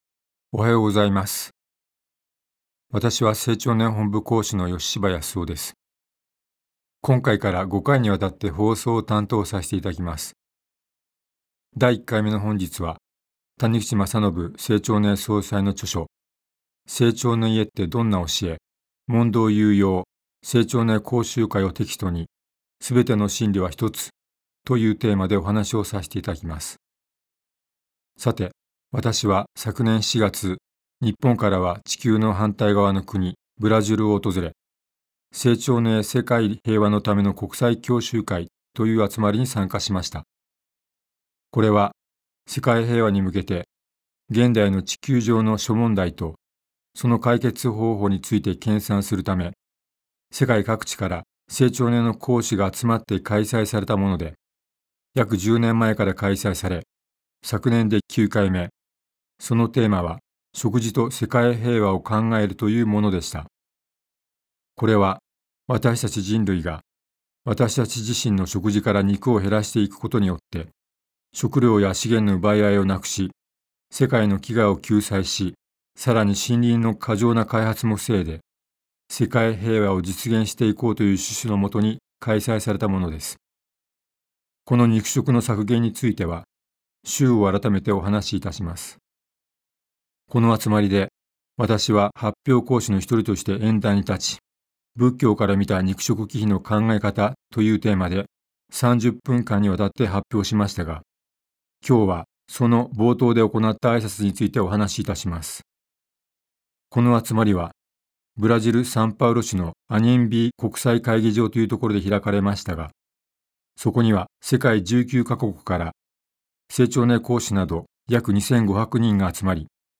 生長の家がお届けするラジオ番組。
生長の家の講師が、人生を豊かにする秘訣をお話しします。